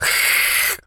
Animal_Impersonations
cat_hiss_02.wav